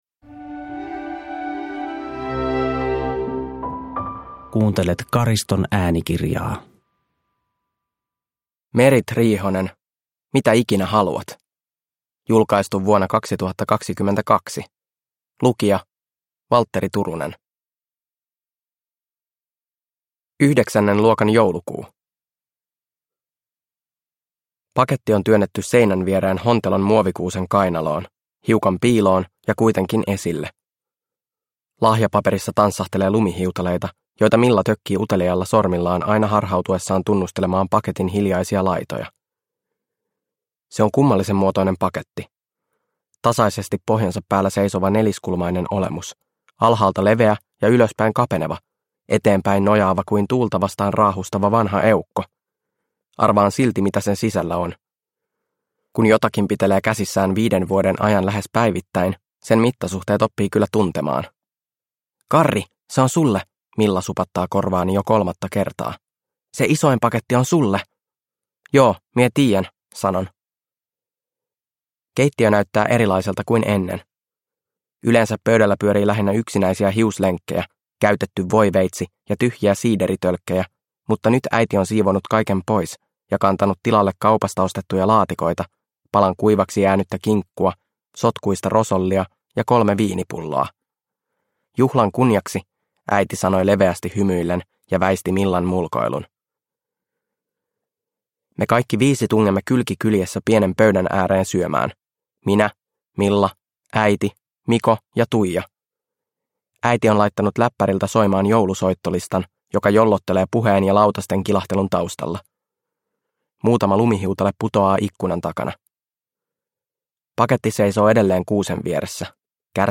Mitä ikinä haluat – Ljudbok – Laddas ner